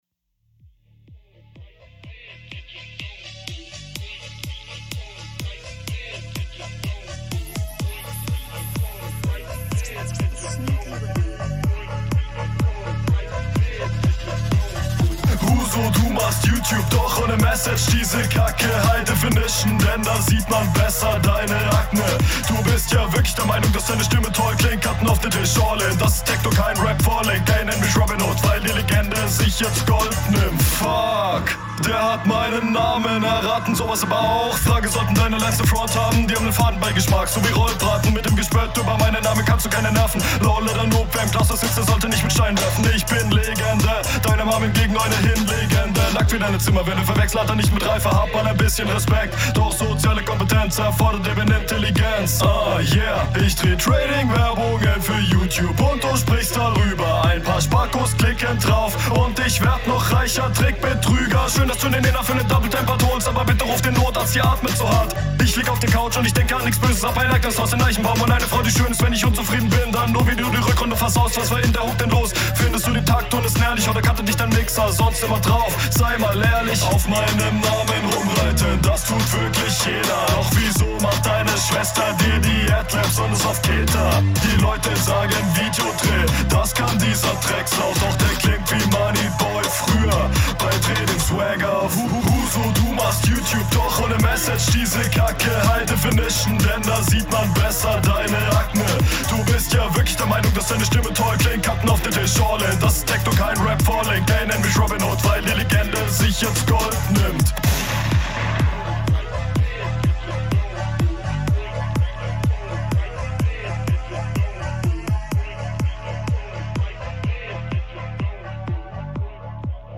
in den highlights gut gerappt, aber zu oft raus aus dem takt um es besser …